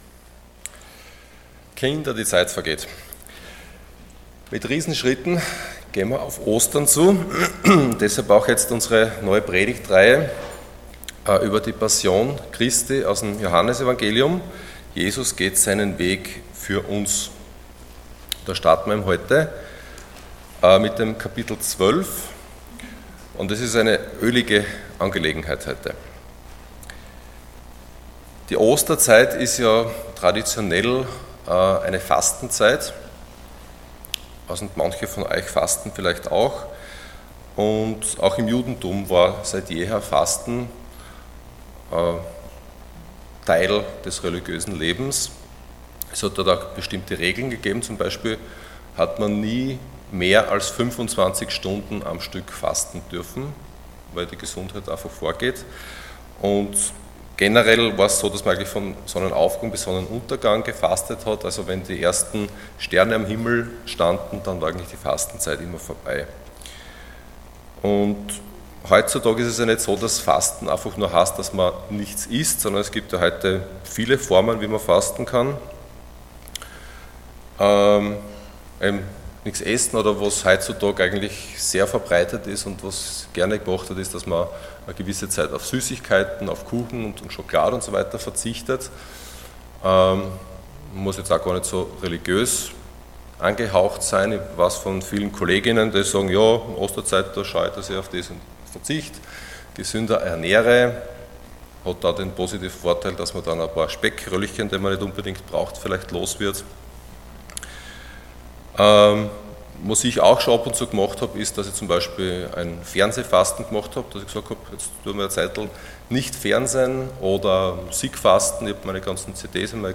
Passage: John 12:1-11 Dienstart: Sonntag Morgen